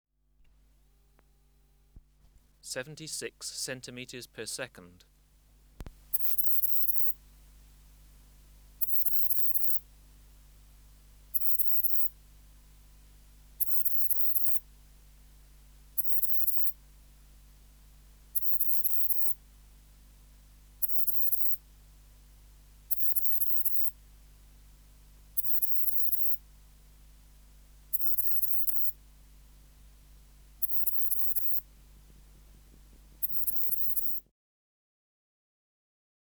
Recording Location: BMNH Acoustic Laboratory
Reference Signal: 1 kHz for 10s
Substrate/Cage: Large recording cage
Distance from Subject (cm): 6 Filter: Low pass, 24 dB per octave, corner frequency 20 kHz